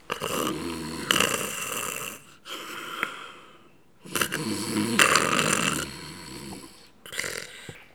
ronflement_01.wav